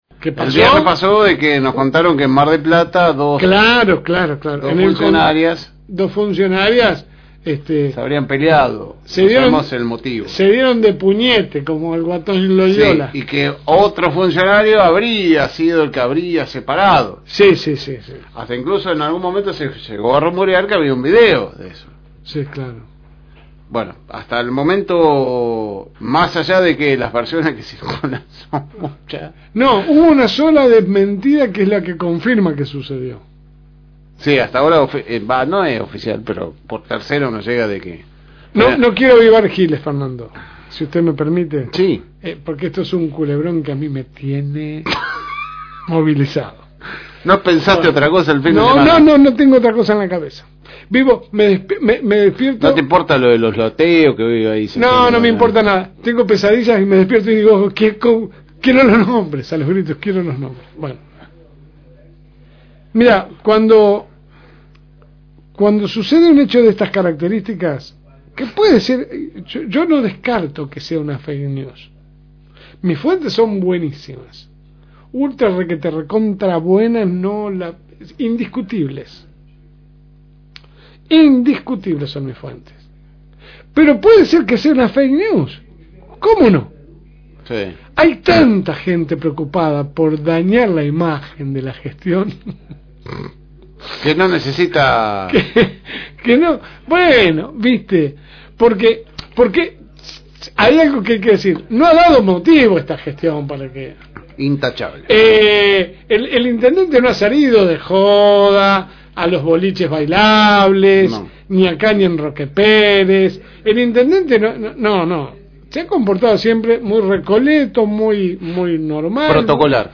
La Segunda Mañana sale de lunes a viernes de 10 a 12 HS por el aire de la Fm Reencuentro 102.9